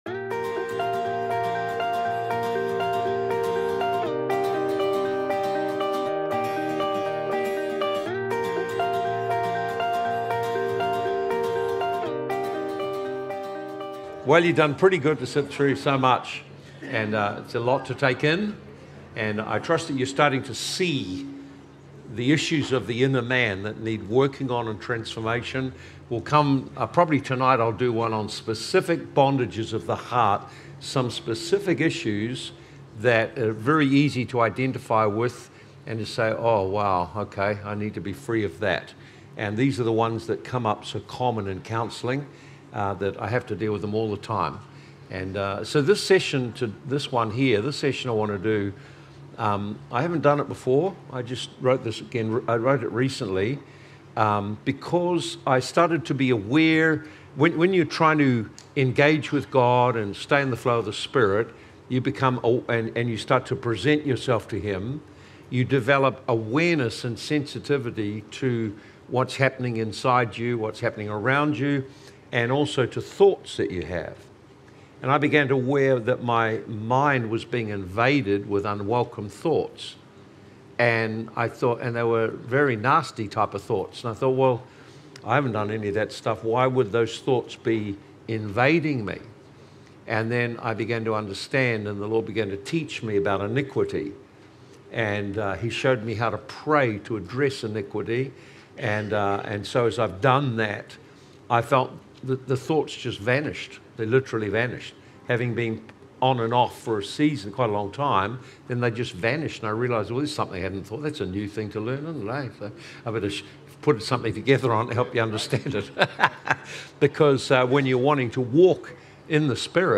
Recorded at Living Waters Christian Centre.